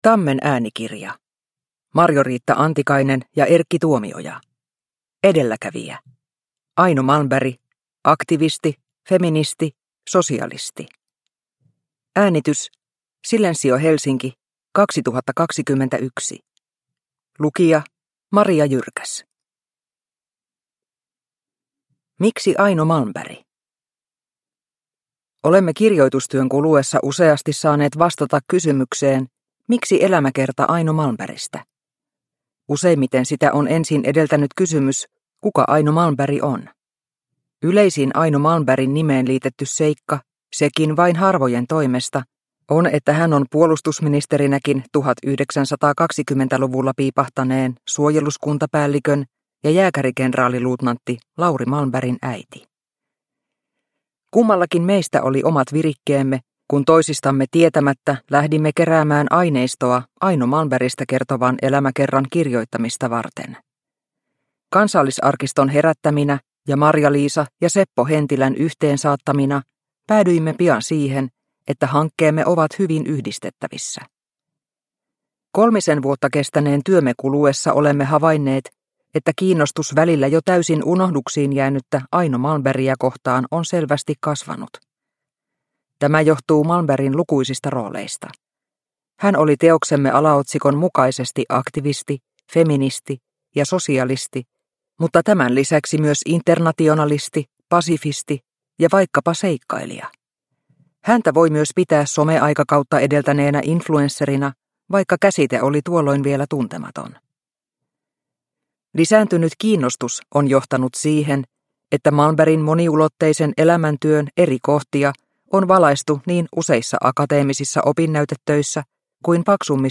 Edelläkävijä – Ljudbok – Laddas ner